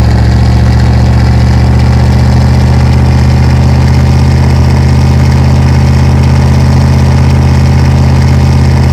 Index of /server/sound/vehicles/lwcars/quadbike
rev.wav